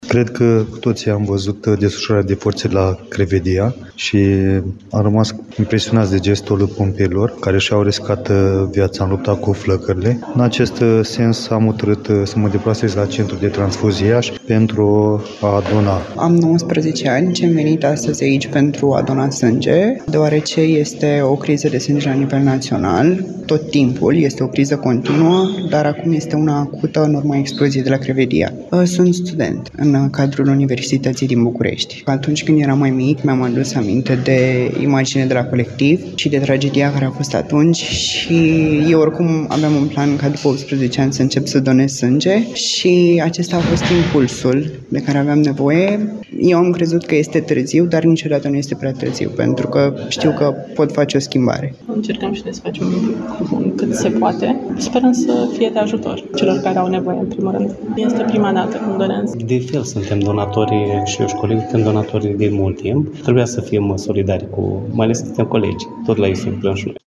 Pompieri, dar și zeci de oameni obișnuiți  au venit și astăzi la Centrul de Transfuzii Sanguine din Iași pentru a dona sânge.
28-aug-rdj-12-vox-pop-centru.mp3